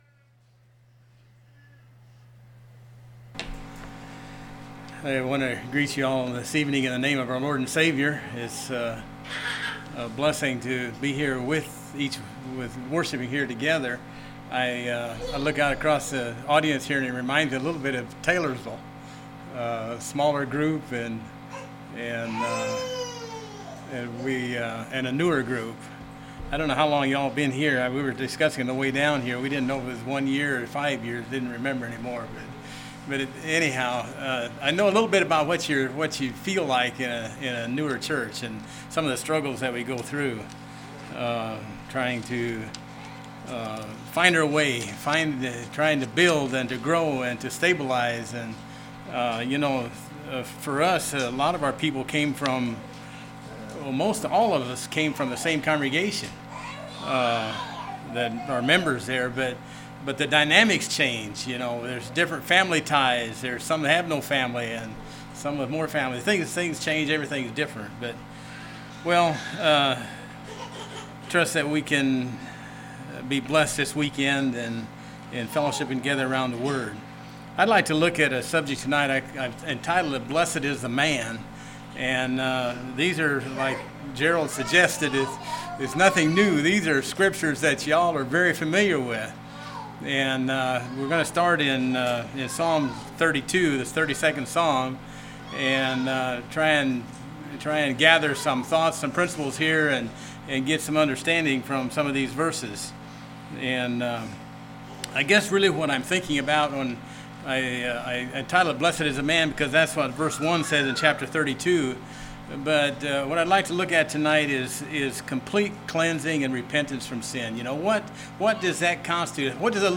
2019 Sermon ID